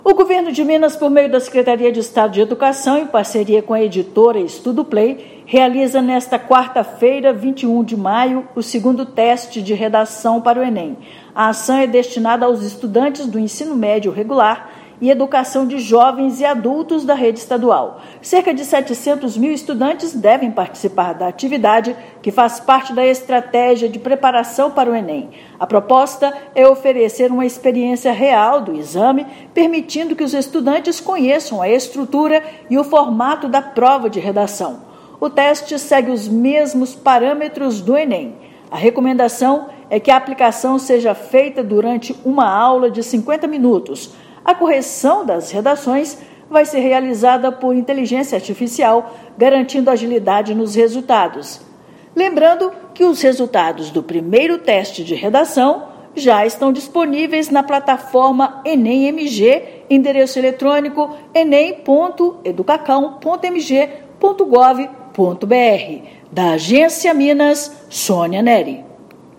Teste para todos estudantes do ensino médio seguirá o mesmo formato do exame e terá correção realizada por Inteligência Artificial. Ouça matéria de rádio.